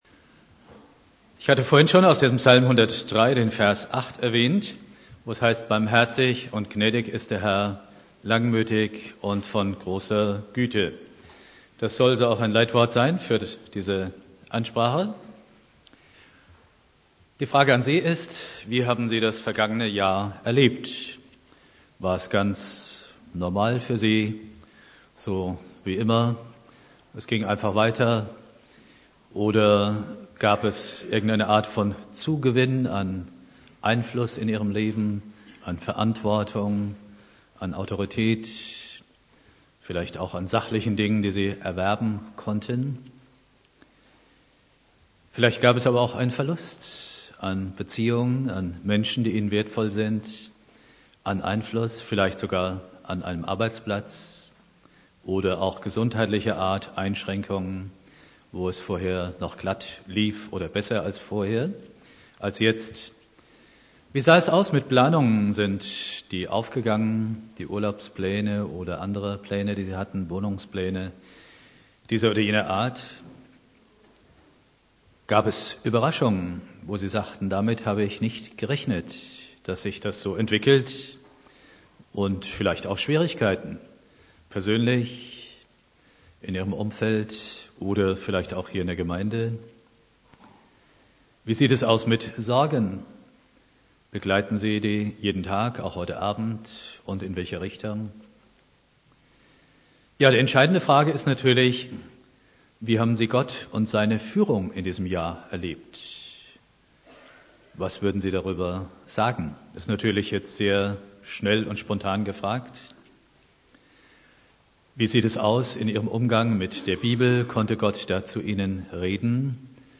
Predigt
Silvester Prediger